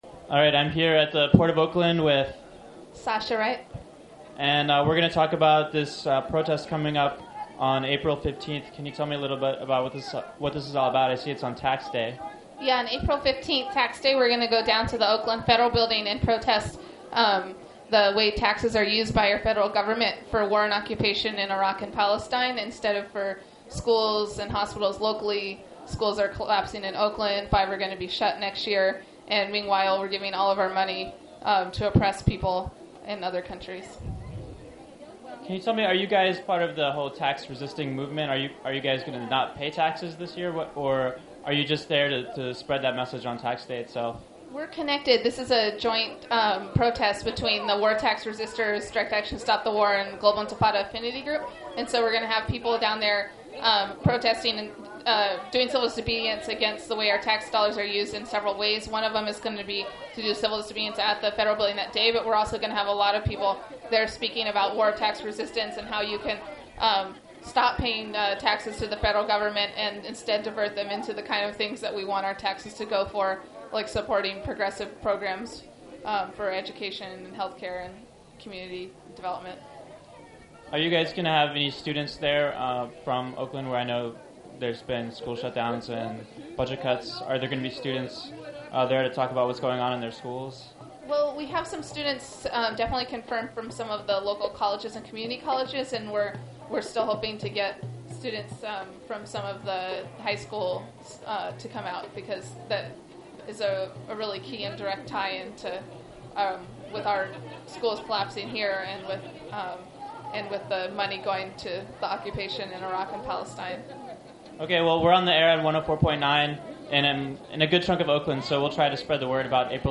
April 7, 2004: One year after Oakland Police attacked the first such direct action against war profiteers APL and SSA, antiwar protesters once again shutdown the SSA docks at the Port of Oakland... here are some audio clips.